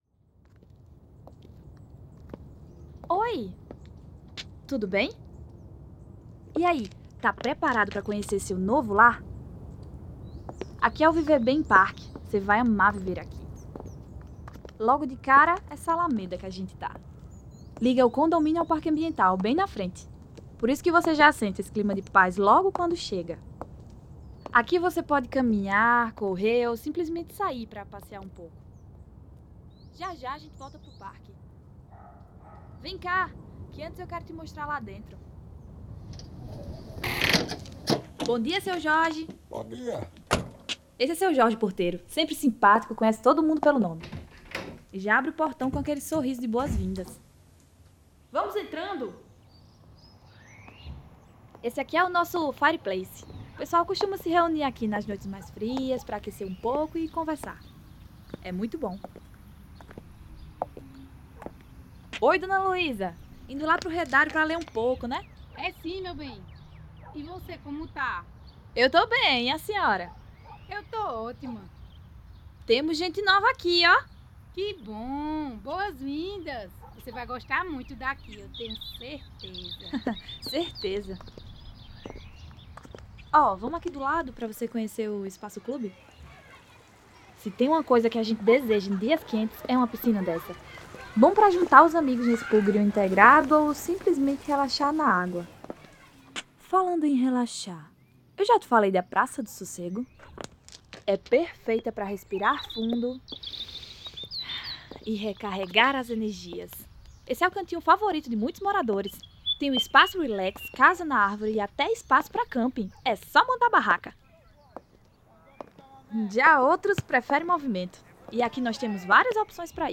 Defesa: Foi criado o primeiro tour sensorial em áudio para um empreendimento imobiliário, utilizando captação e mixagem binaural para simular presença realista nos ambientes do condomínio. A experiência guiava o ouvinte por portaria, áreas de lazer, piscina, parque infantil e convivência, com camadas de som ambiente e vozes em perspectiva espacial.
VIVER BEM BINAURAL.mp3